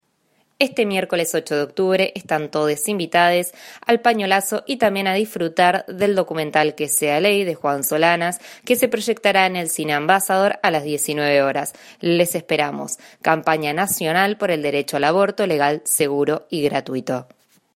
integrante de la campaña en apoyo al Aborto Legal en Argentina: